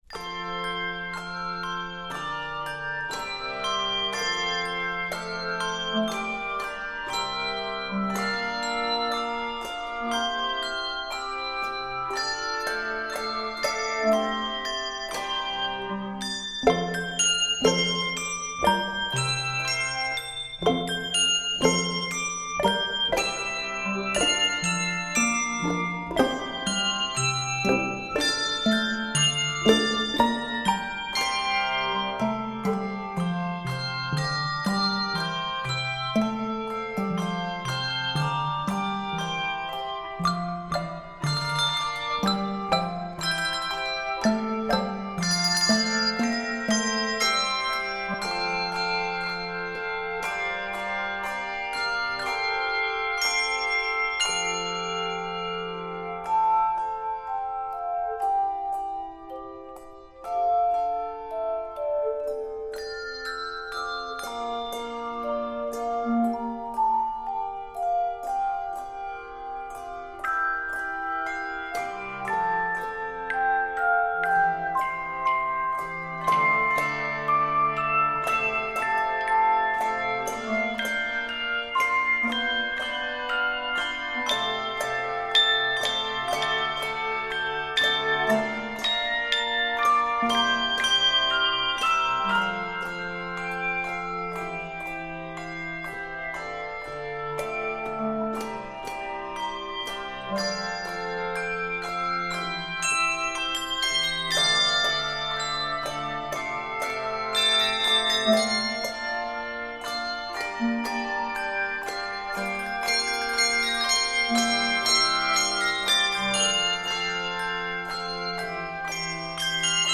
light and ethereal original work